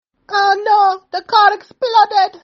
This is a great sound effect lol:
oh-no-the-car-exploded.mp3